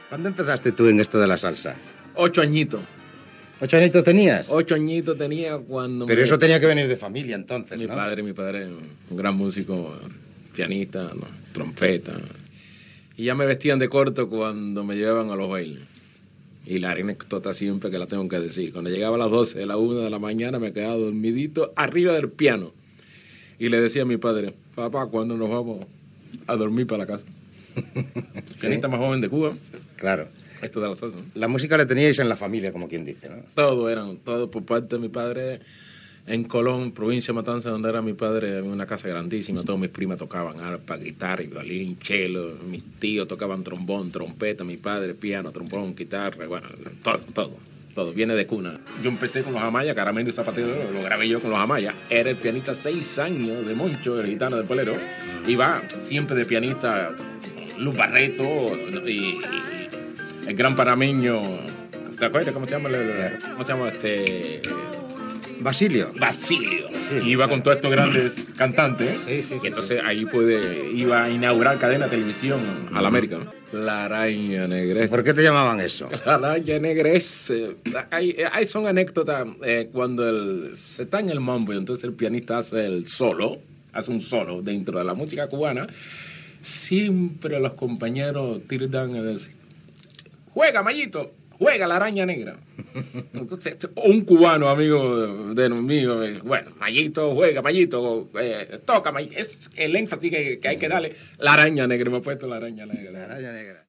Entrevista
Musical